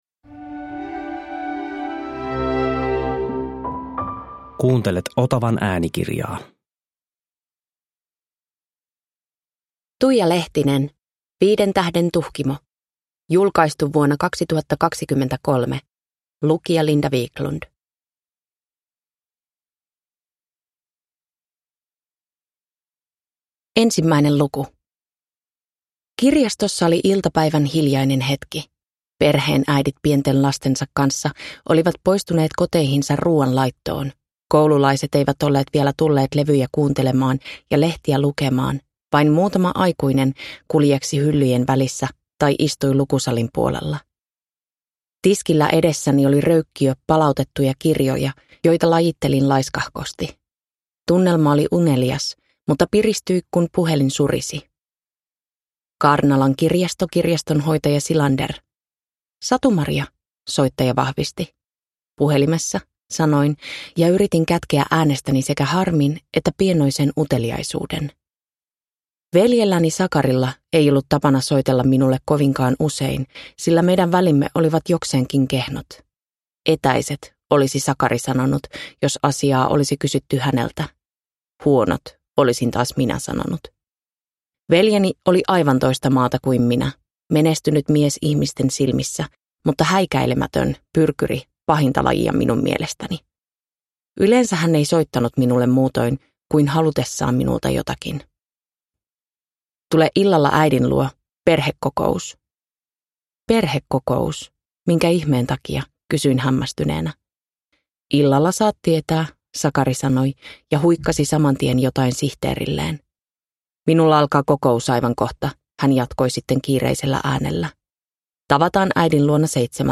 Viiden tähden Tuhkimo – Ljudbok – Laddas ner